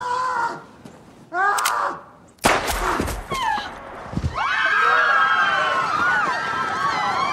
• yelling shot echoing woman gasps crowd screaming Sound-Effect].ogg
[yelling]-[shot-echoing]-[woman-gasps]-[crowd-screaming-sound-effect]_iis.wav